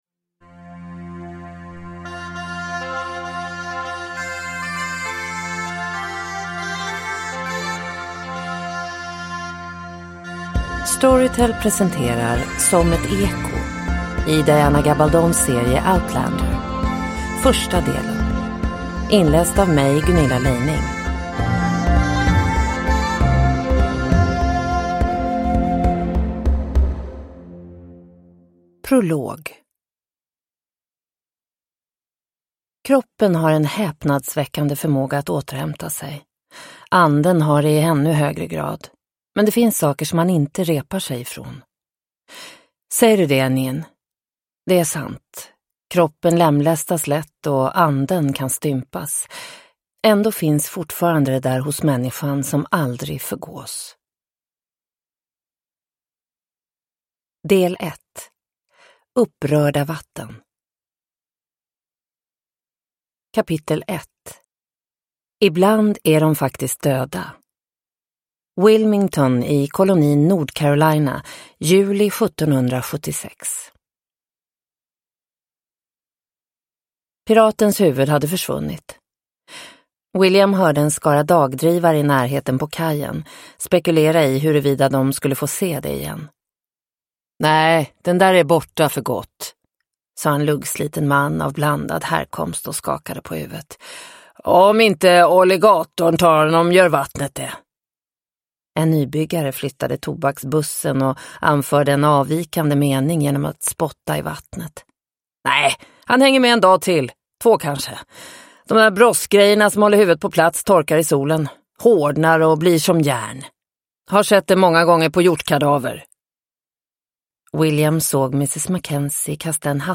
Som ett eko - del 1 – Ljudbok – Laddas ner